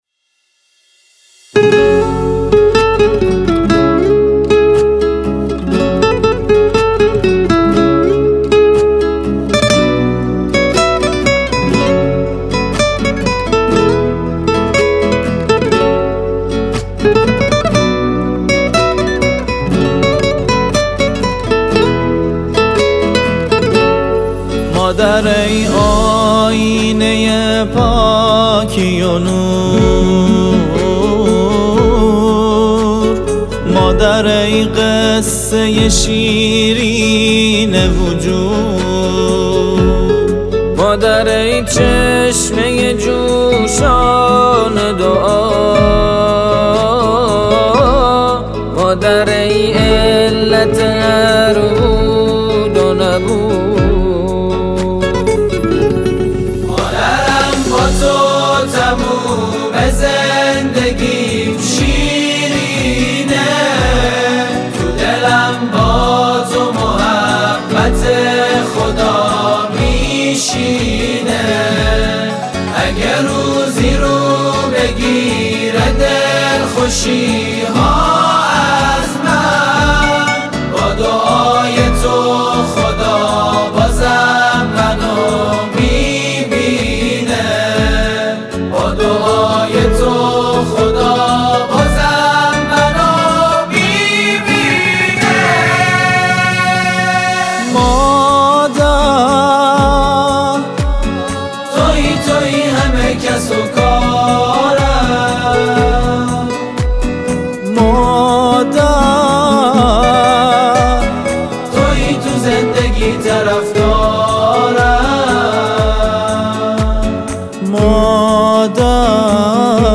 اجرا: گروه هم آوایی نجوا / تهیه شده در استودیو